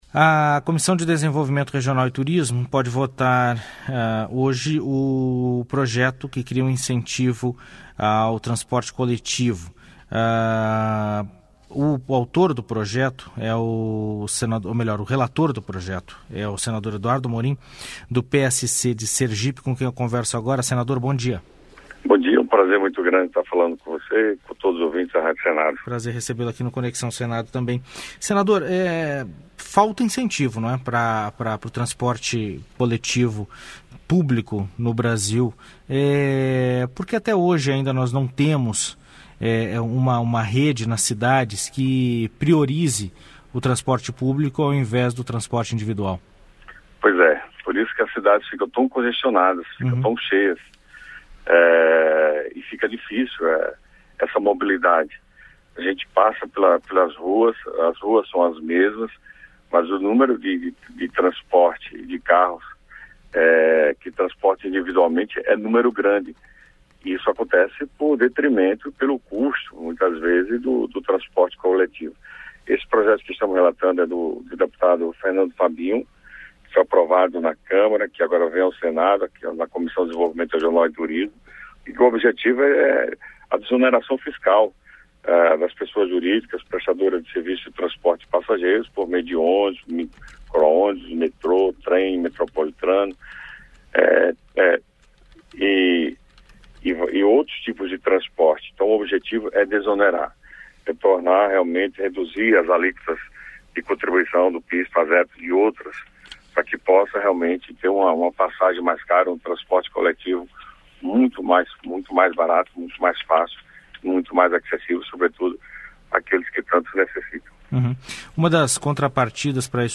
Entrevista com o senador Eduardo Amorim (PSC-SE), relator da matéria na Comissão de Desenvolvimento Regional.